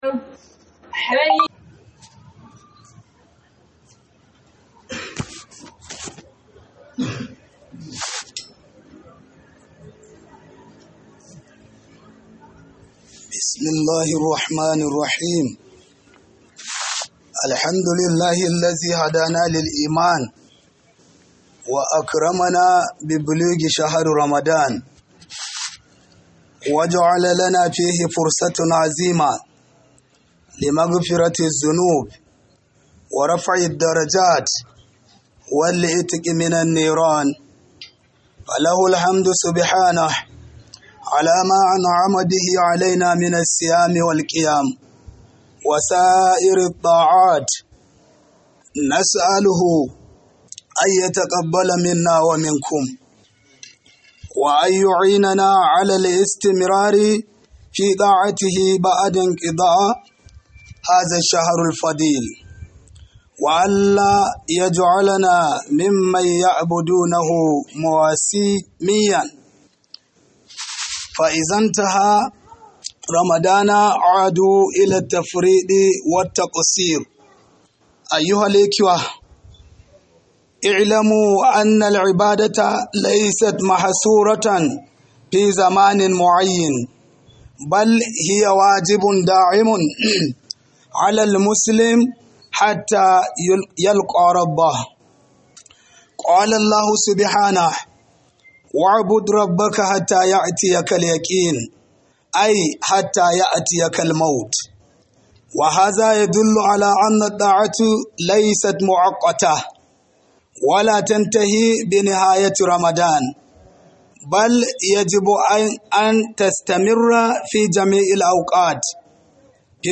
Khudubar Jibwis Burra - Ayyukan mu bayan Ramadan - Khudubar Sallar Juma'a by JIBWIS Ningi
Khudubar Jibwis Burra - Ayyukan mu bayan Ramadan